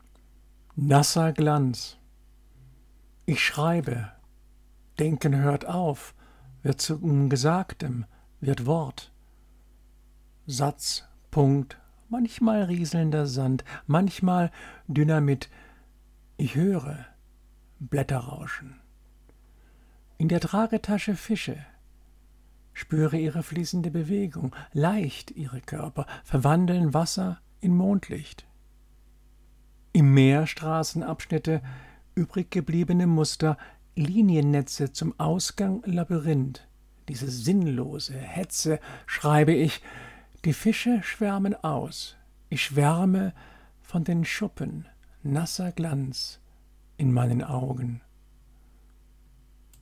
Gedichte – rezitiert
Insofern möchte ich hierbei so manche Gedichte für euch rezitieren.